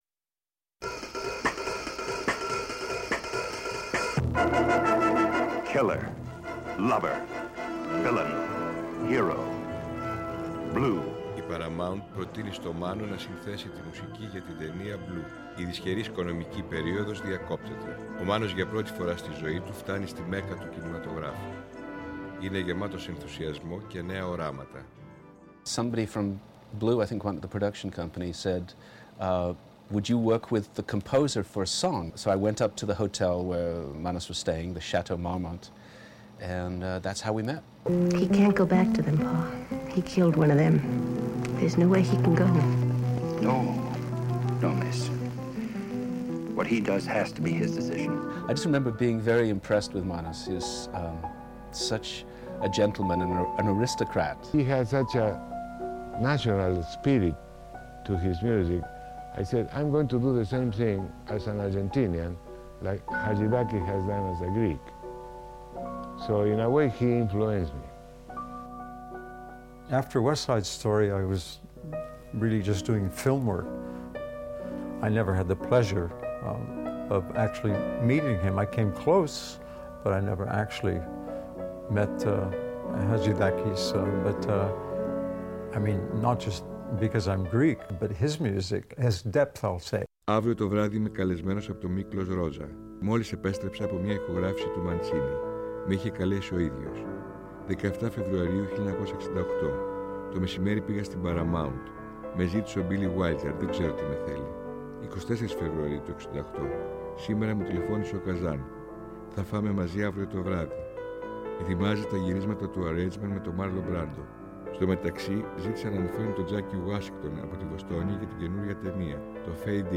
σε μία έκτακτη ζωντανή εκπομπή